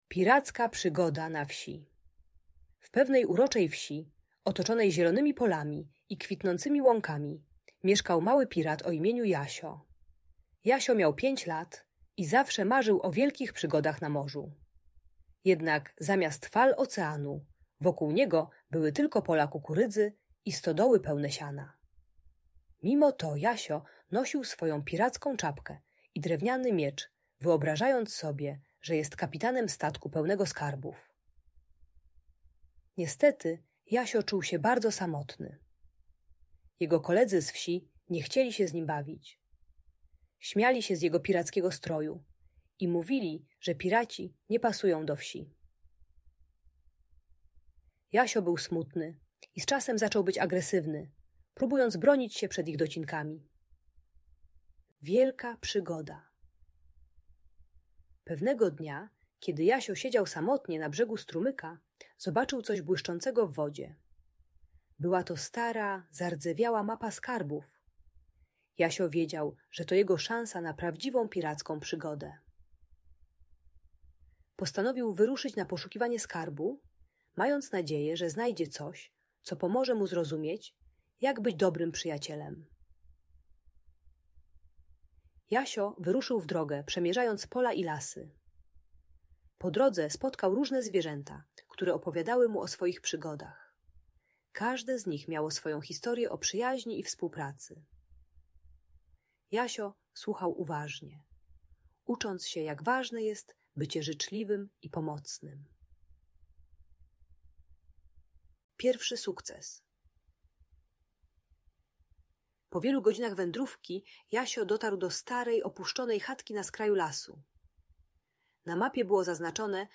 Piracka przygoda na wsi - historia o przyjaźni - Audiobajka dla dzieci